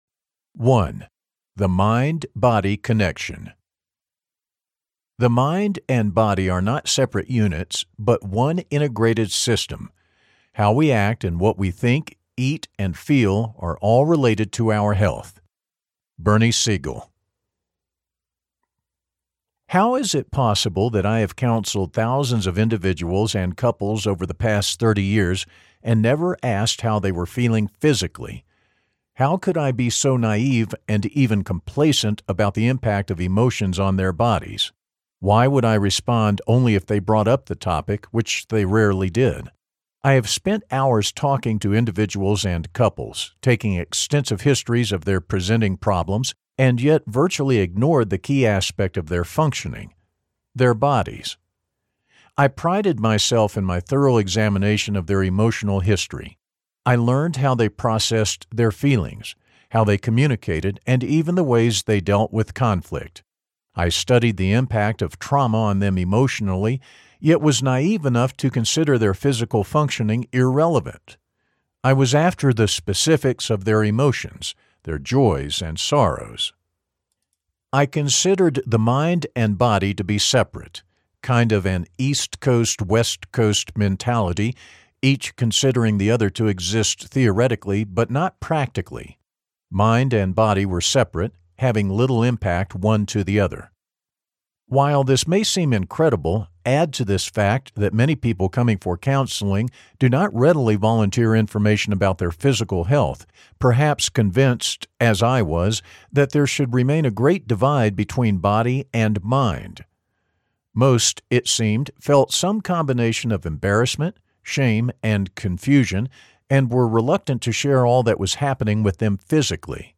In Sickness and in Health Audiobook
7.86 Hrs. – Unabridged